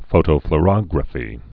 (fōtō-fl-rŏgrə-fē, -flô-, -flō-)